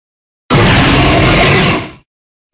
Cri de Kyurem Noir dans Pokémon Noir 2 et Blanc 2.
(discussion | contributions)Catégorie:Cri de Kyurem Catégorie:Cri Pokémon (Noir et Blanc)